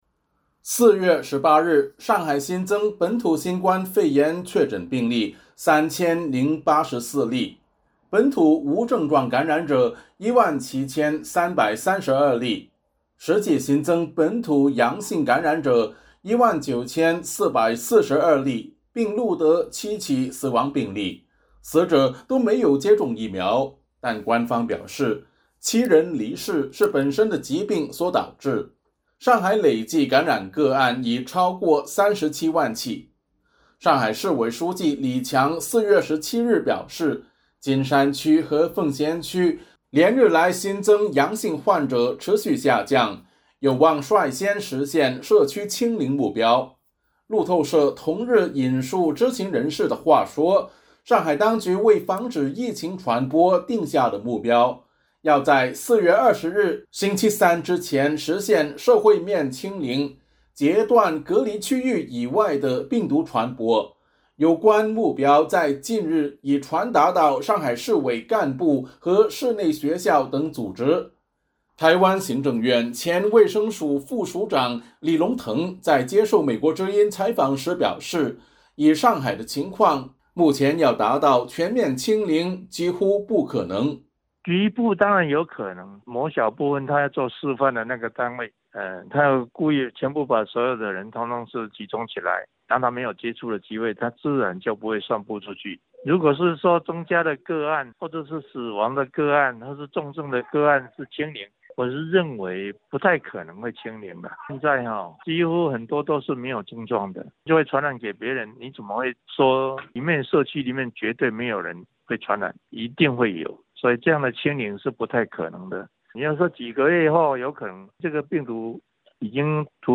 中国疾控中心流行病学首席专家吴尊友19日在新闻发布会表示，动态清零不等于零感染，但对于每一起疫情都要求在较短时间内消灭，重申动态清零仍是目前中国防控策略的最佳选择。